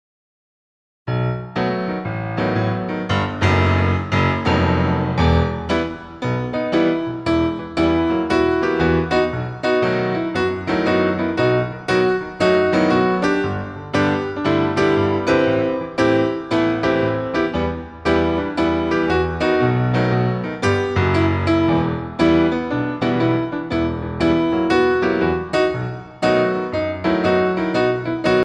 TENDU IN THE CENTRE